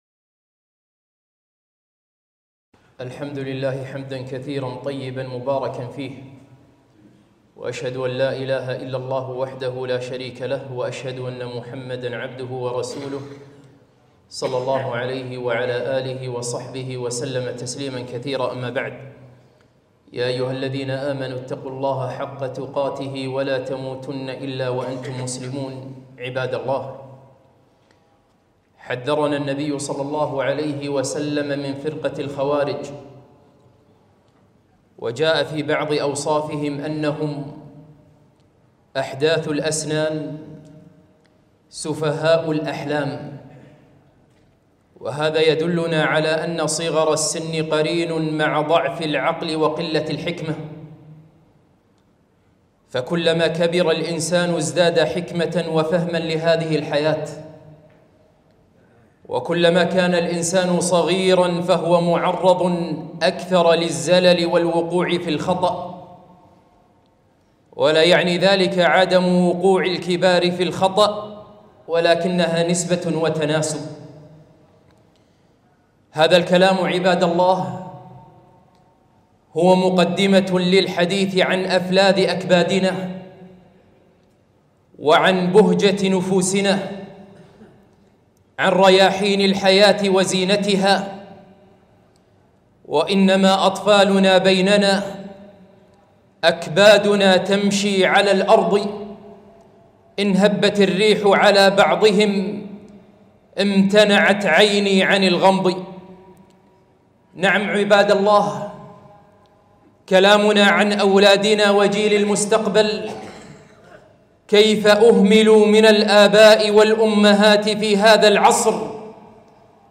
خطبة - لا تتركوا برامج التواصل تربي أولادكم